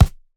GDYN_Punching_Perc_RAW_SG